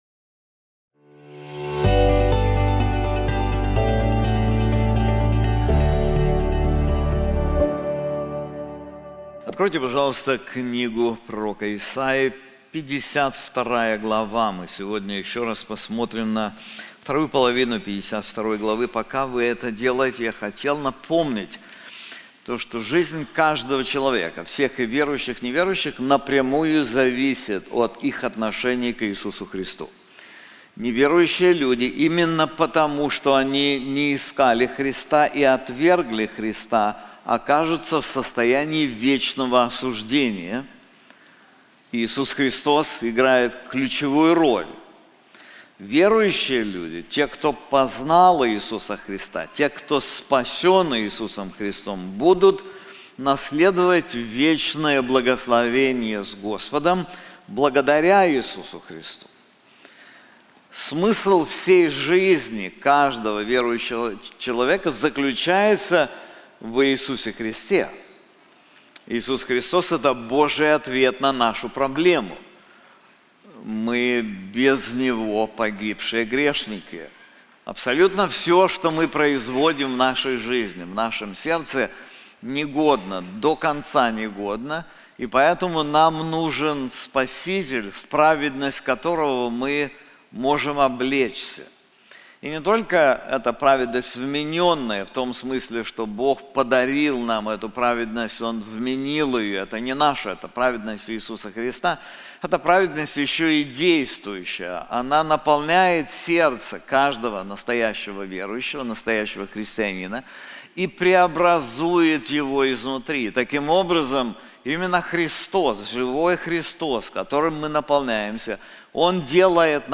This sermon is also available in English:The Successful Servant • Isaiah 52:13-15